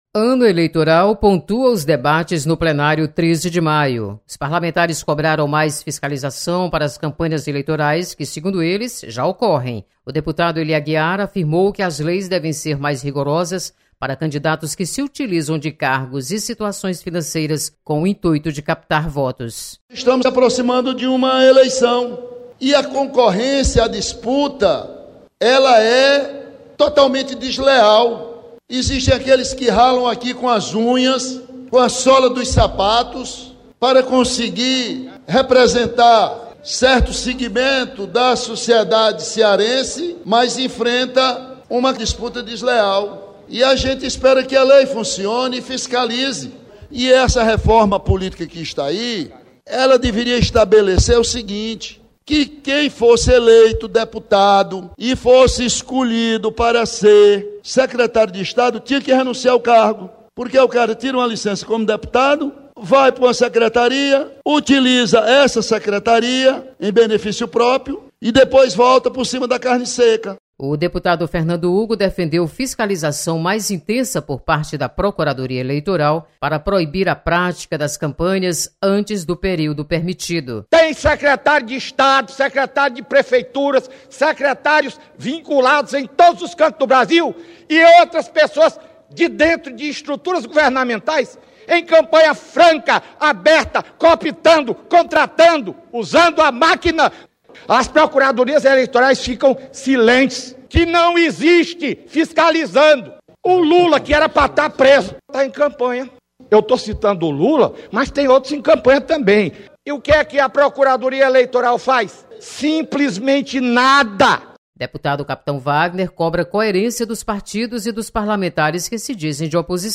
Deputados cobram fiscalização rigorosa neste ano eleitoral. Repórter